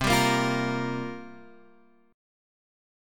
Listen to C7sus4 strummed